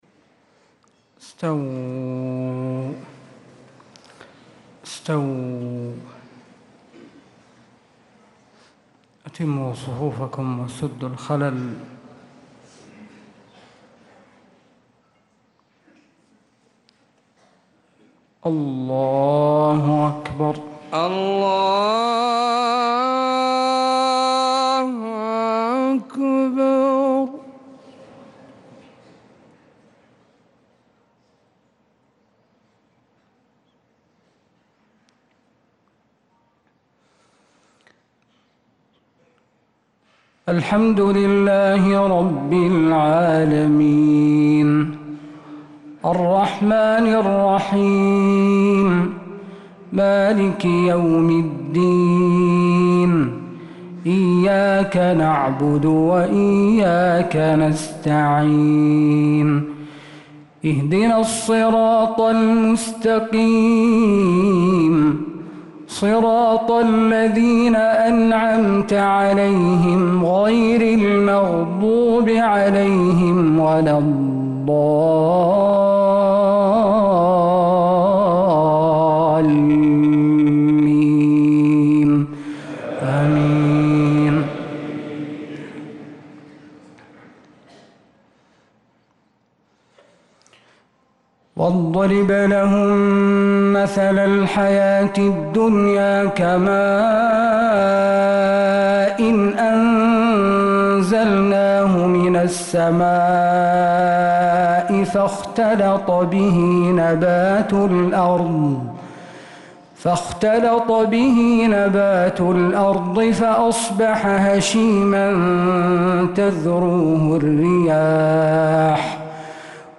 صلاة المغرب